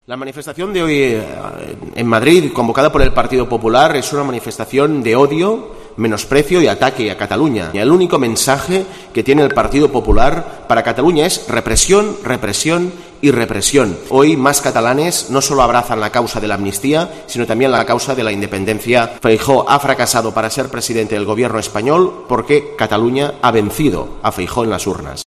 "El único mensaje que tiene el PP para Cataluña es represión", ha dicho el líder del Ejecutivo catalán en una rueda de prensa antes de reunirse con la ministra de Cultura de Chile.